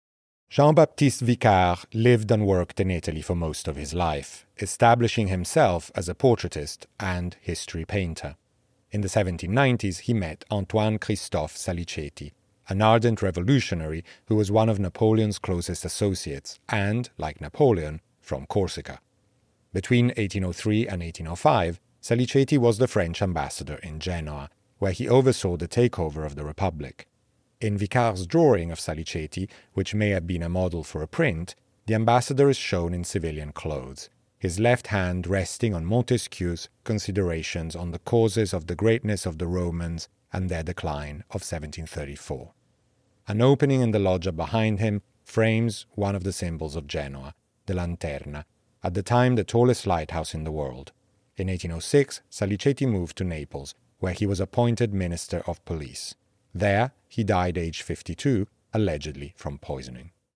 Each object is accompanied by an audio recording of the label text.